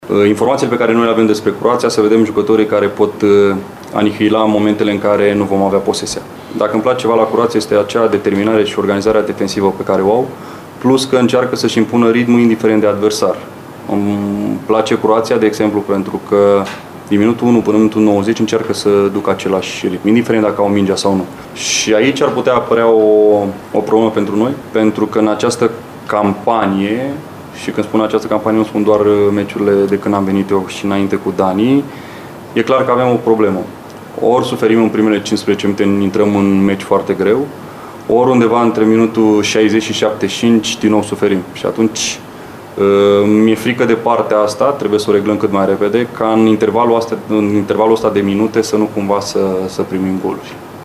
Selecționerul Mirel Rădoi a vorbit, înaintea startului de turneu, despre abordarea primului joc la Euro Under 21: